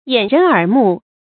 注音：ㄧㄢˇ ㄖㄣˊ ㄦˇ ㄇㄨˋ
掩人耳目的讀法